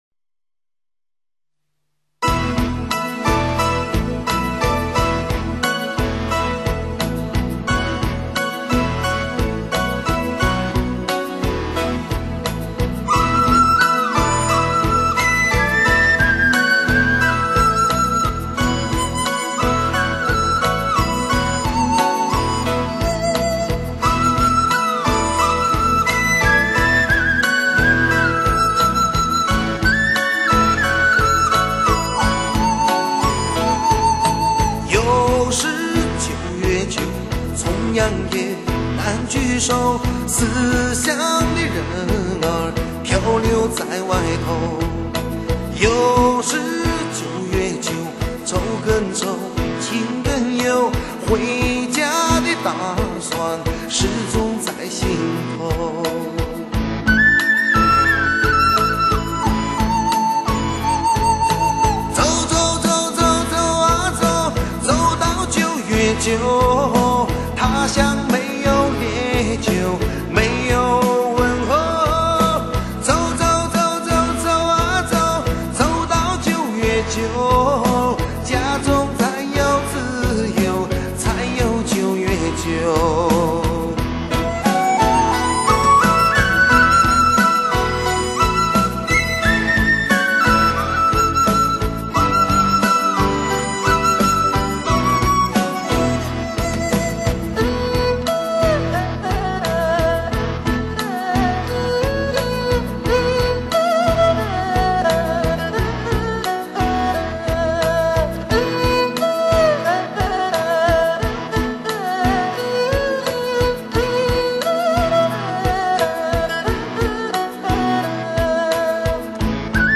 慢四舞曲